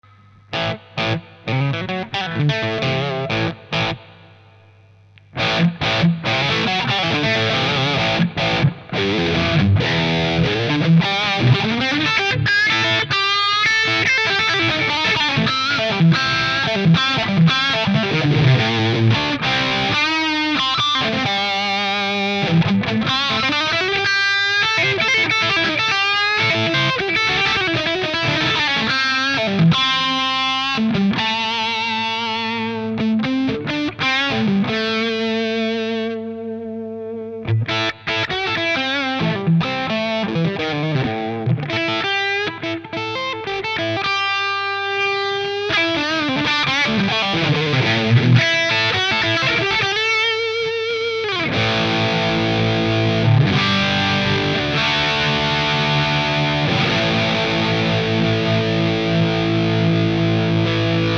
La pedale dans le canal plexi de mon ampli (on entend le son sans la pedale au debut). La baisse de gain a un moment, c'est qd je joue avec le potard de ... la gratte !